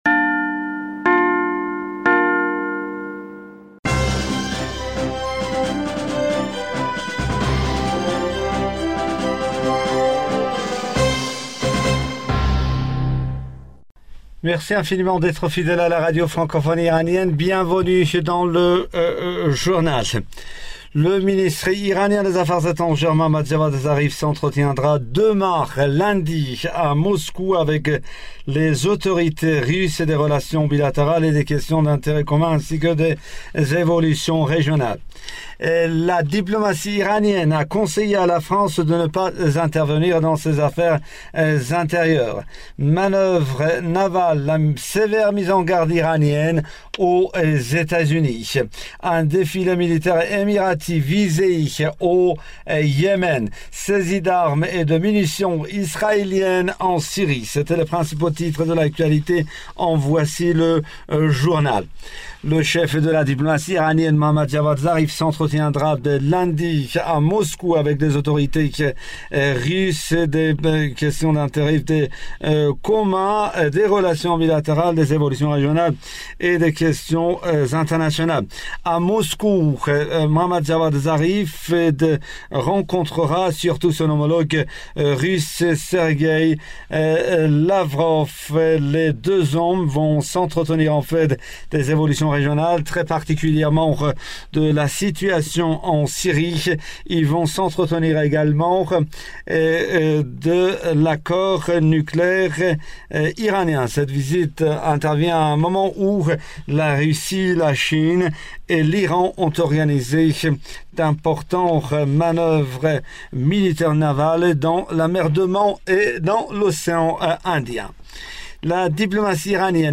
Bulletin d'information du 29 decembre